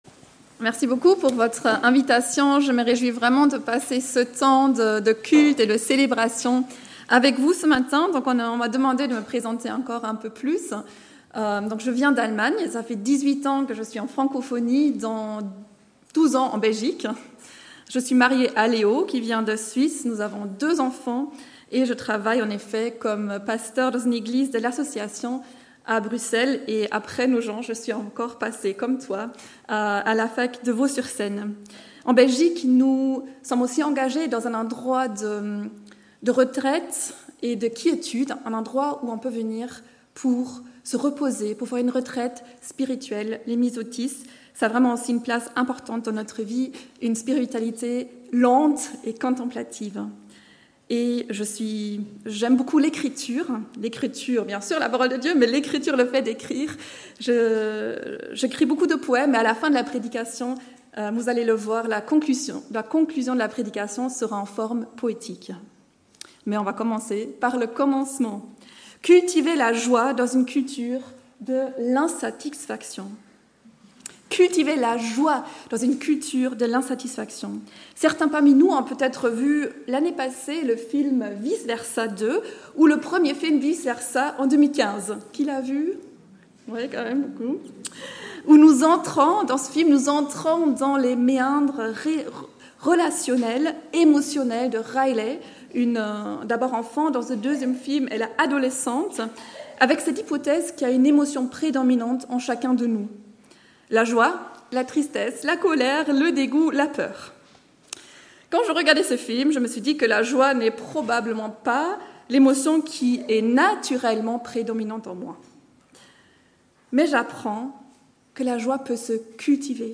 Culte du dimanche 21 septembre 2025 – Église de La Bonne Nouvelle